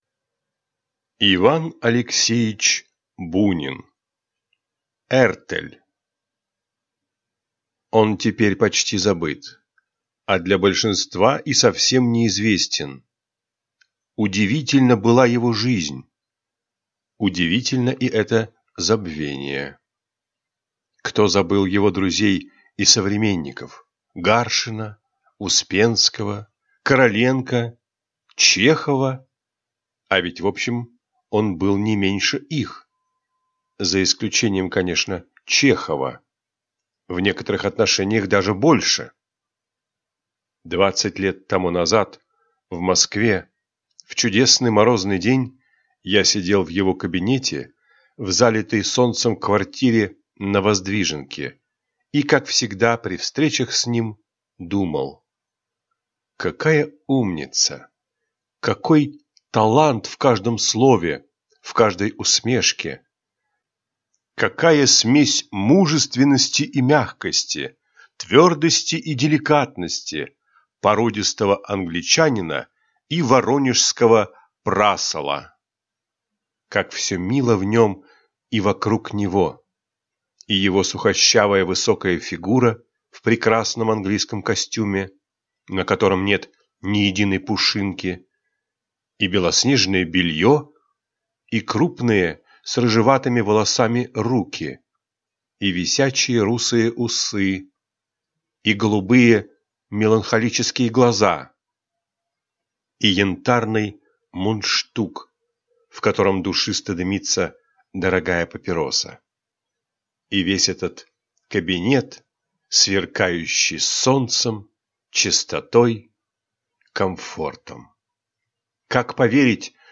Aудиокнига Эртель Автор Иван Бунин